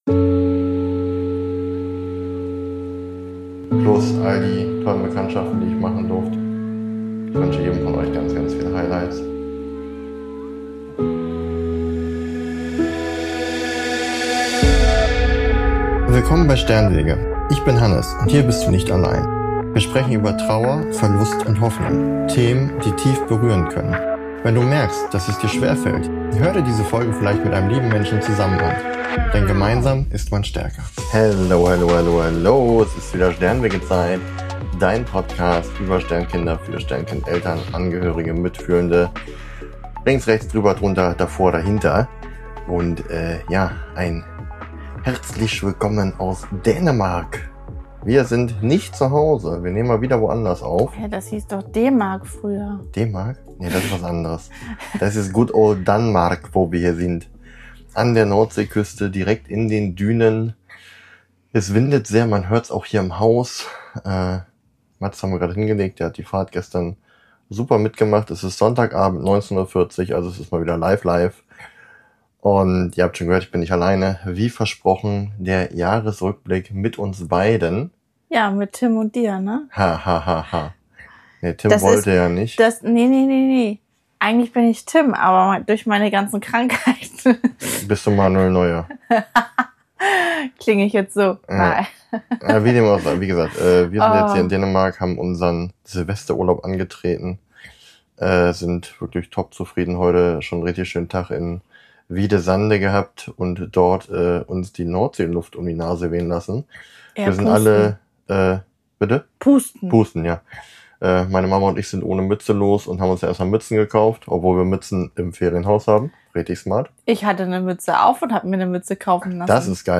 direkt aus den Dünen, mit Nordseewind ums Ferienhaus